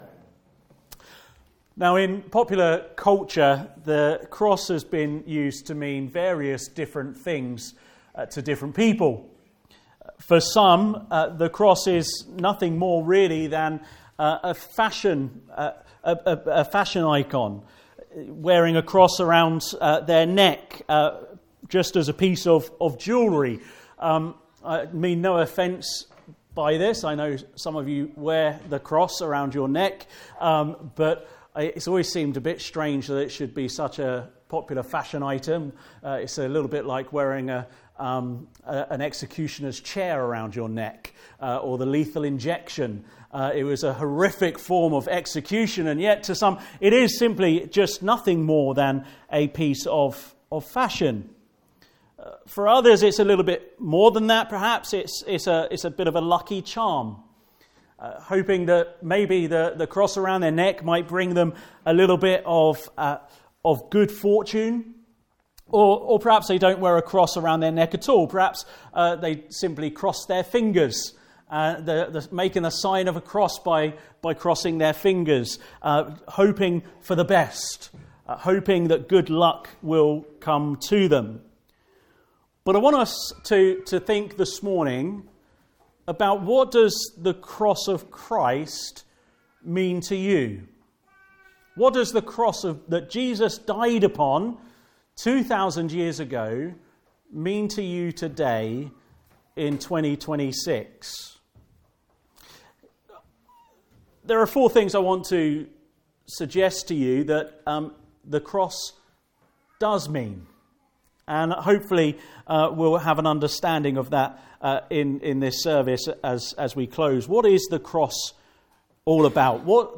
1-20 Service Type: Morning Service Special Service What Does the Cross Mean to You?